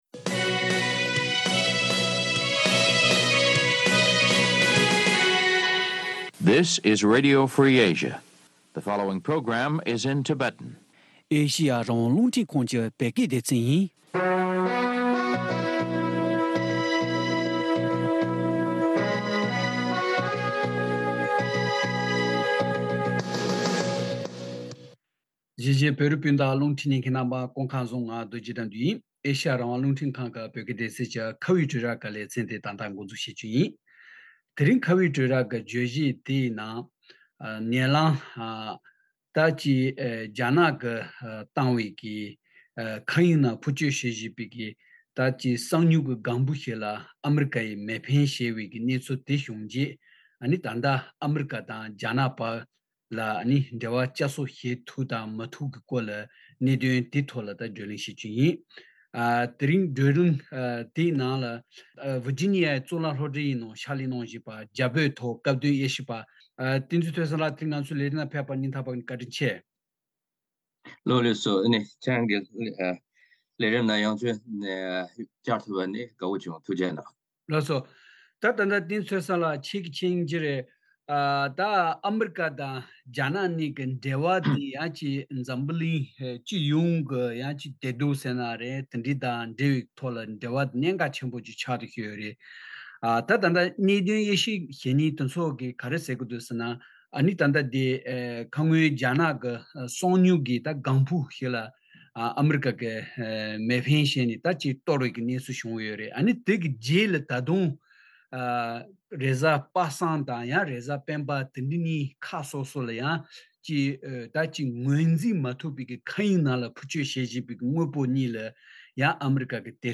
ཐེངས་འདིའི་ཁ་བའི་གྲོས་རྭའི་ལེ་ཚན་ནང་།